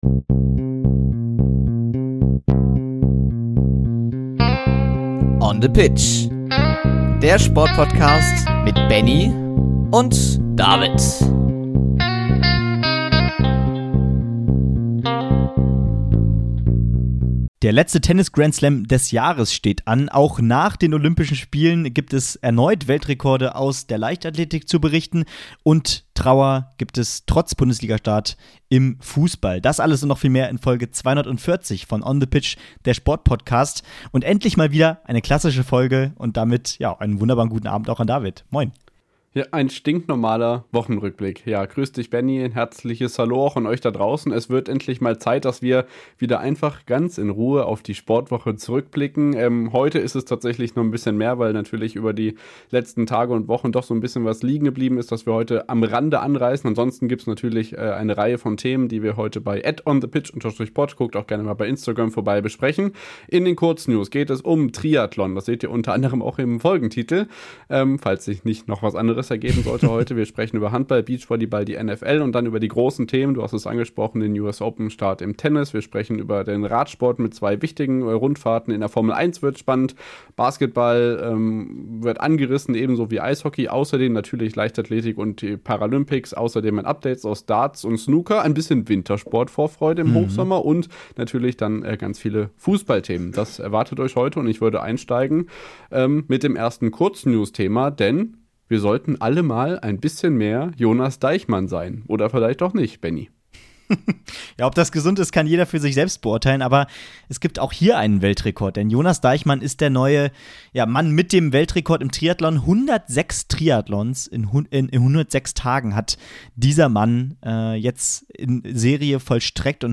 Endlich wieder klassischer Wochenrückblick im altbekannten Duo!